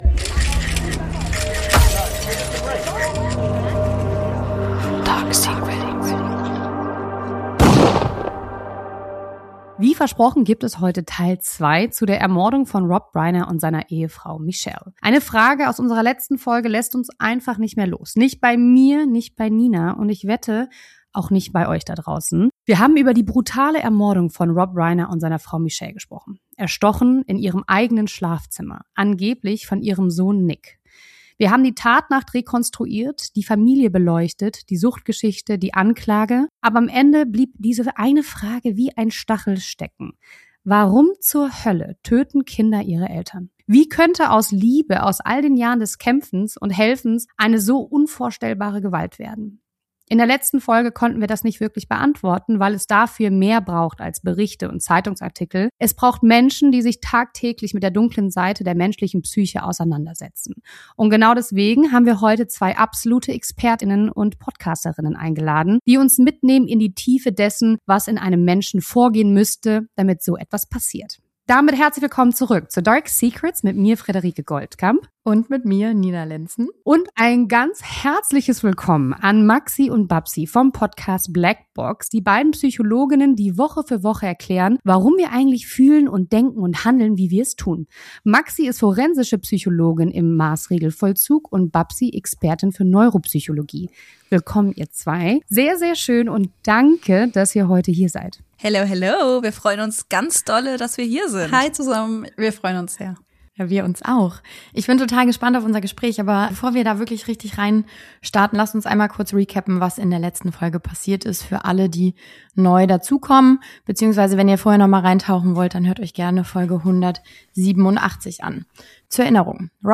Was treibt Kinder dazu, die Menschen anzugreifen, die sie am meisten lieben sollten? In dieser Folge sprechen wir mit zwei Psychologinnen über die zerstörerische Kraft von Sucht, Wahn und familiärem Druck – und darüber, warum solche Tragödien oft viel früher beginnen, als man augenscheinlich denkt.